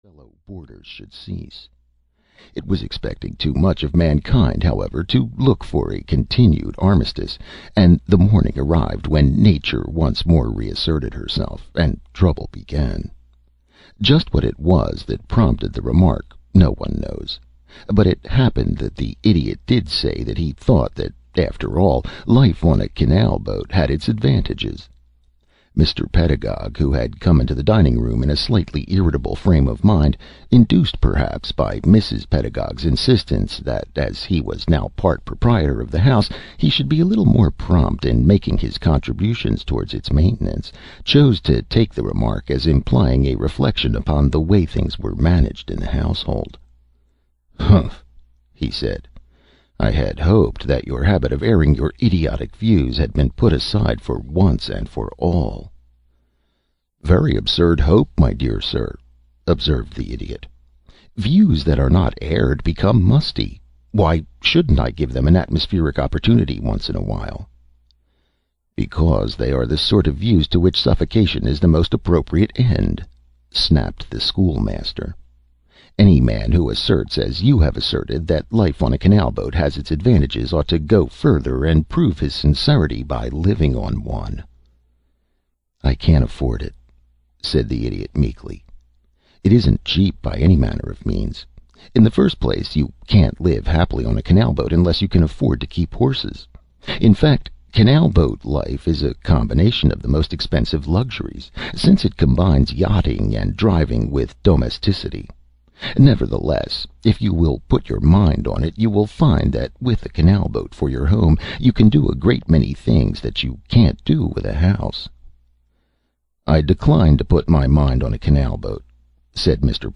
Audio knihaThe Idiot (EN)
Ukázka z knihy